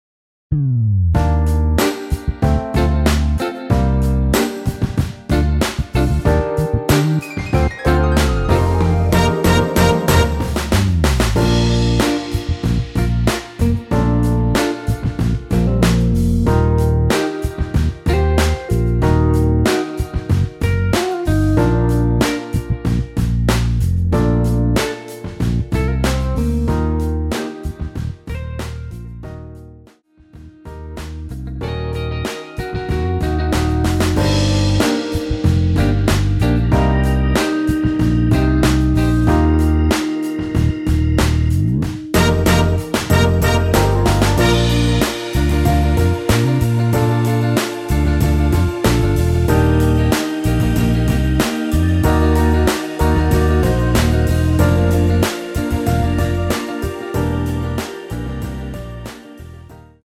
엔딩이 페이드 아웃이라 라이브 하기 좋게 원곡 3분 6초쯤에서 엔딩을 만들었습니다.(원키 미리듣기 참조)
Bb
앞부분30초, 뒷부분30초씩 편집해서 올려 드리고 있습니다.
중간에 음이 끈어지고 다시 나오는 이유는